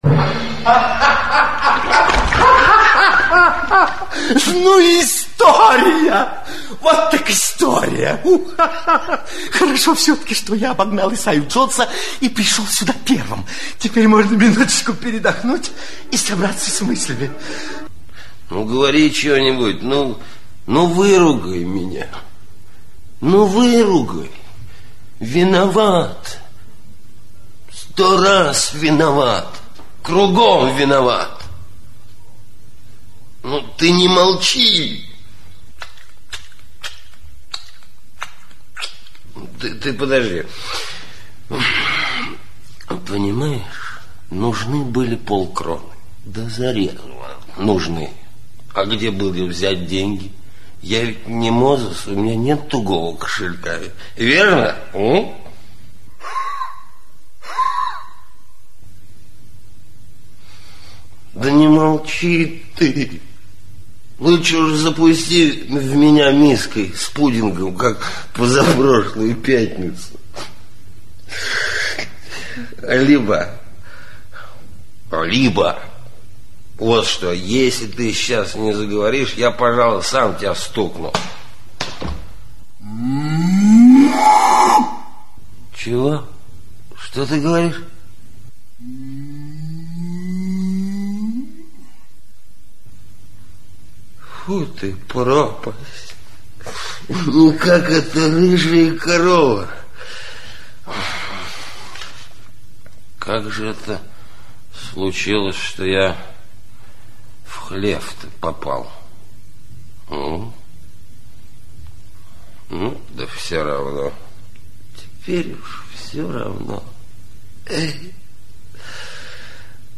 Аудиокнига Жена за полкроны (спектакль) | Библиотека аудиокниг
Aудиокнига Жена за полкроны (спектакль) Автор Рональд Митчелл Читает аудиокнигу Актерский коллектив.